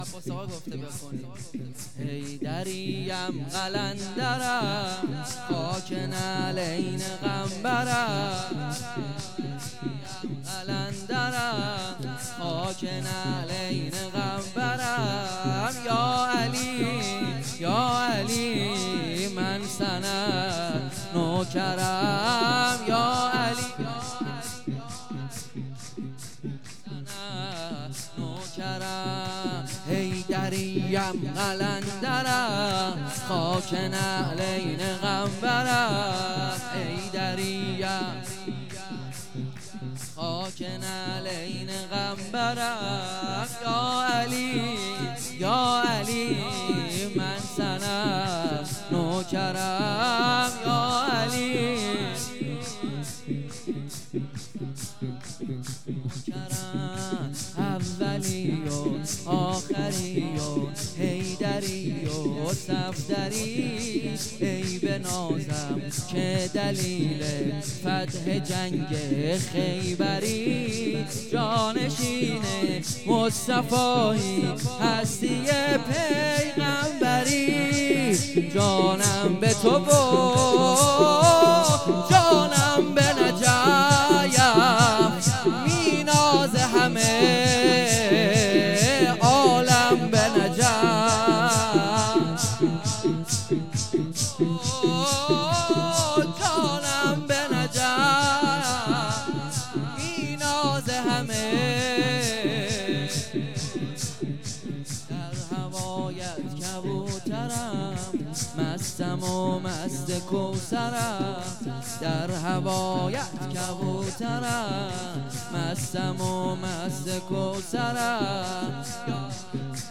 ولادت امام زمان(عج)